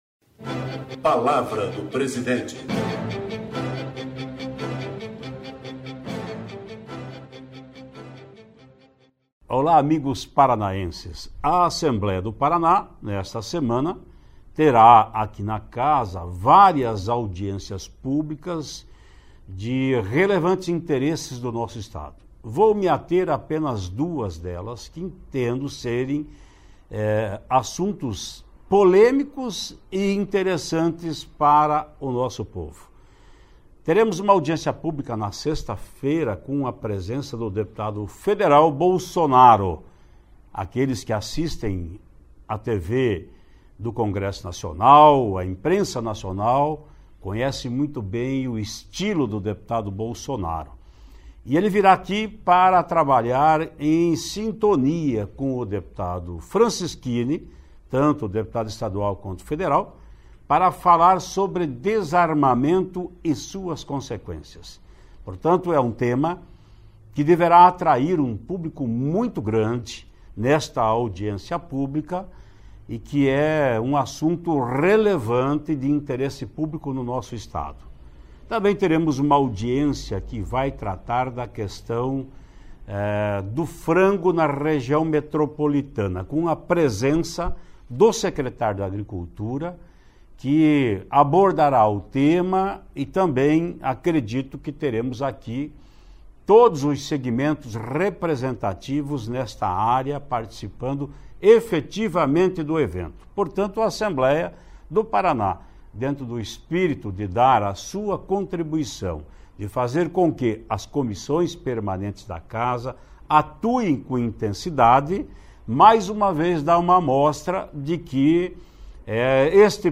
No Palavra do Presidente desta segunda-feira Ademar Traiano fala sobre duas audiências públicas que acontecem na Assembleia Legisaltiva nesta semana.